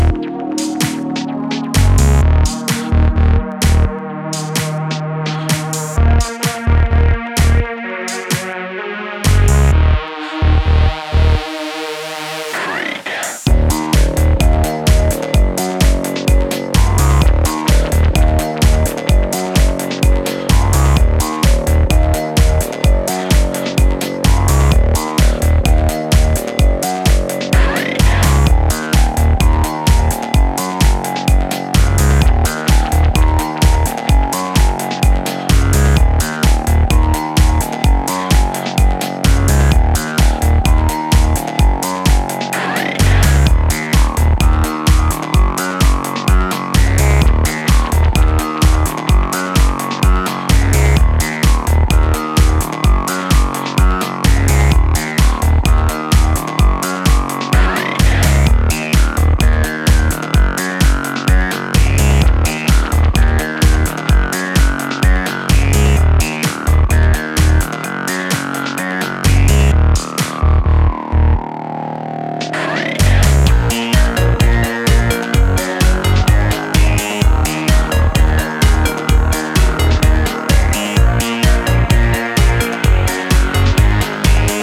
who hands out 3 track in perfect Electro House style.